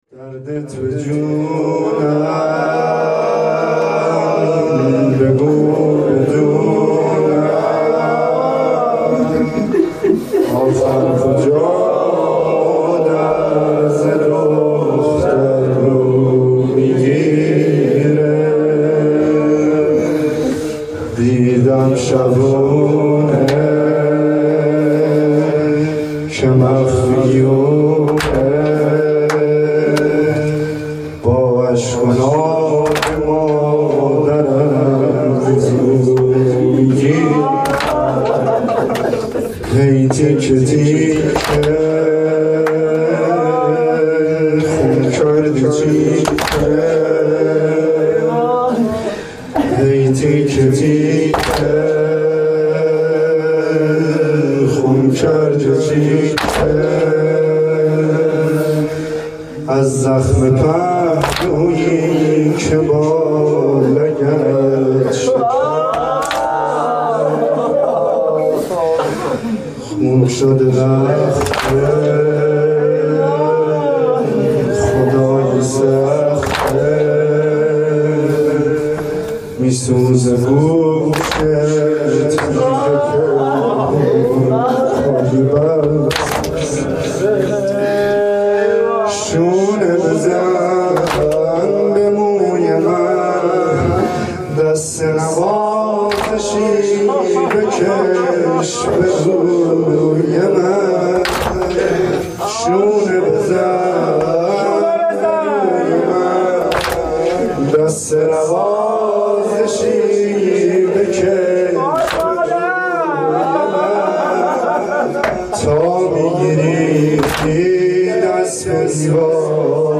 حسینیه
مداحی فاطمیه